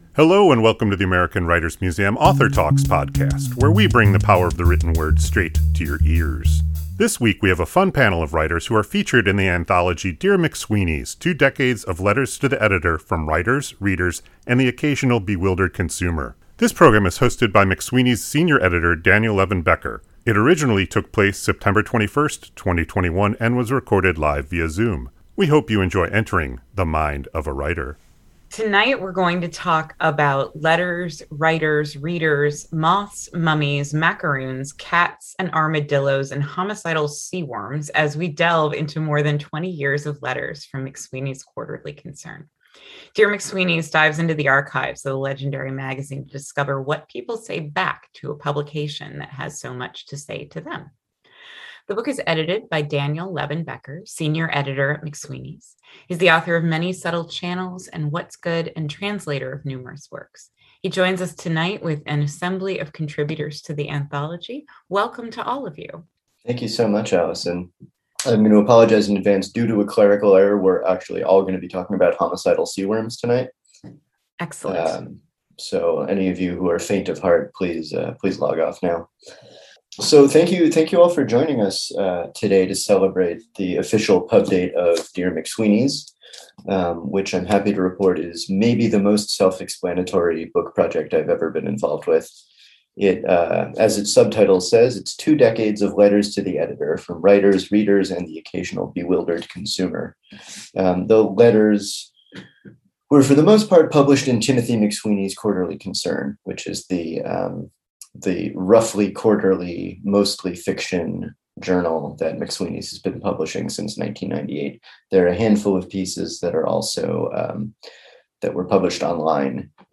Writers featured in the anthology "Dear McSweeney’s" read and discuss their work.
This week, we have a fun panel of writers who are featured in the anthology Dear McSweeney’s: Two Decades of Letters to the Editor from Writers, Readers, and the Occasional Bewildered Consumer.